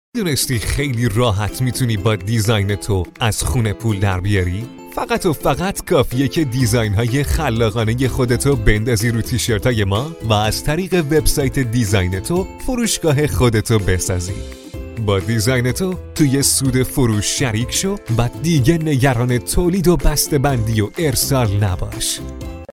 Male
Young
Adult
2 Commercial